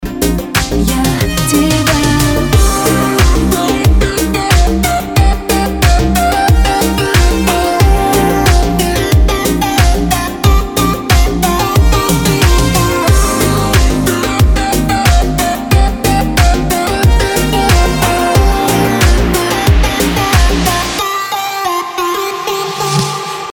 • Качество: 320, Stereo
поп
deep house
nu disco
Русский дипчик